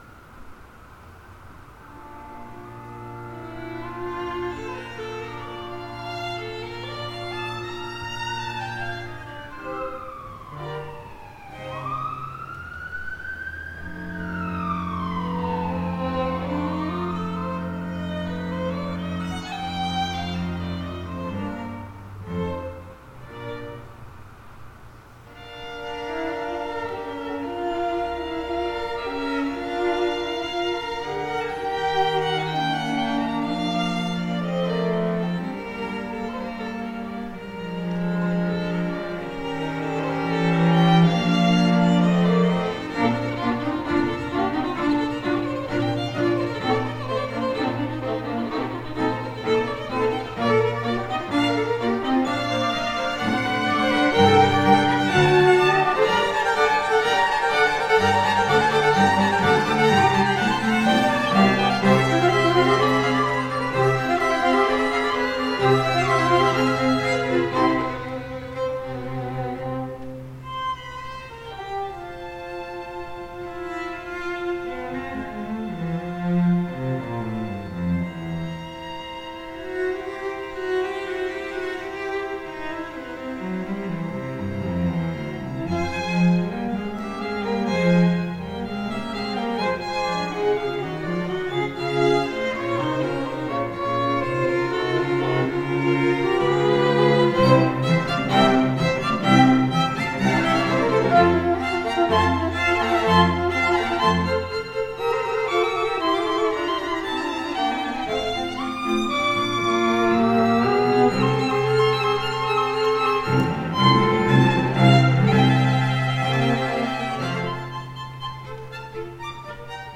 the students
Chamber, Choral & Orchestral Music
Chamber Groups